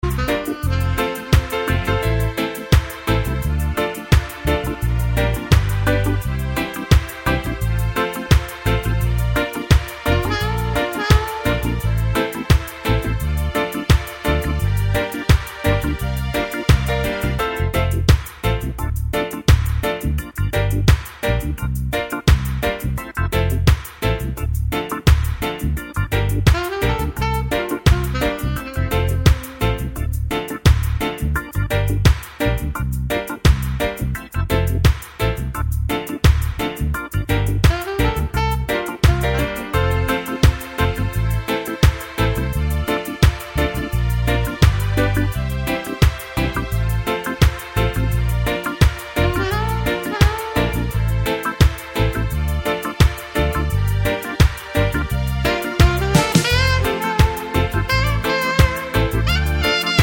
no Backing Vocals Reggae 3:43 Buy £1.50